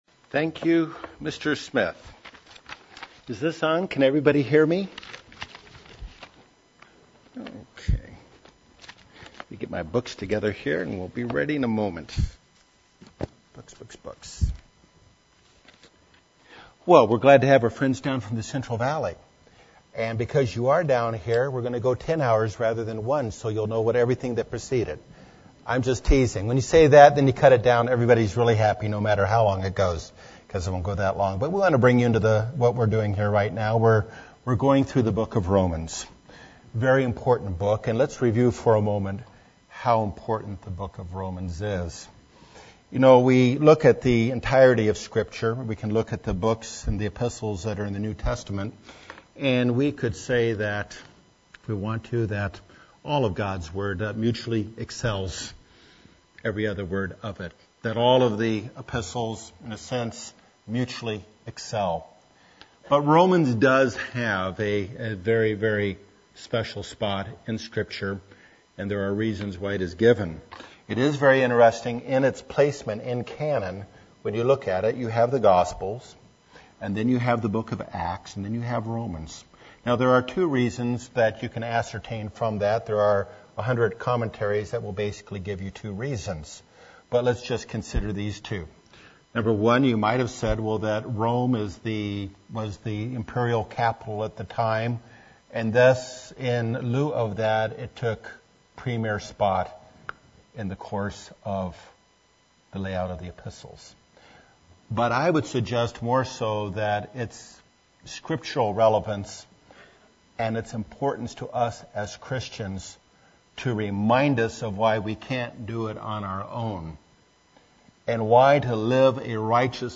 The beginning of a Bible study of the book of Romans. Living a righteous life apart from God is not possible.